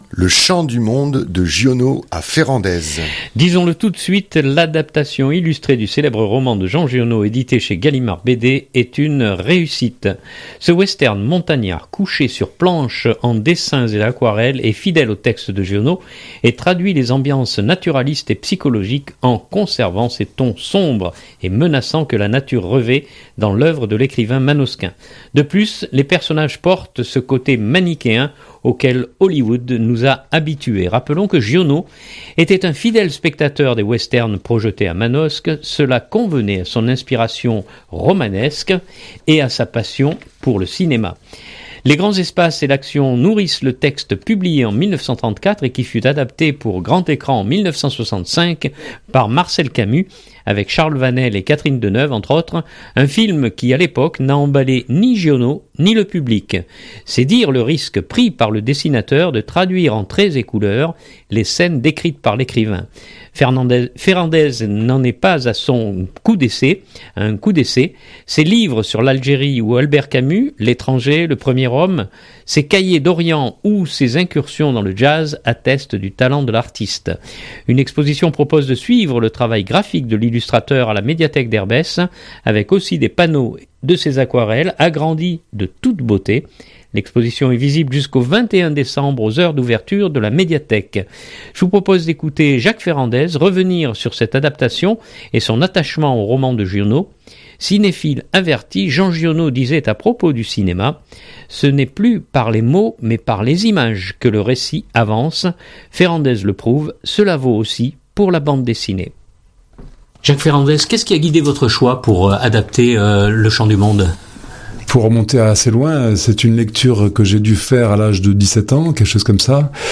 Je vous propose d’écouter Jacques Ferrandez revenir sur cette adaptation et son attachement au roman de Giono.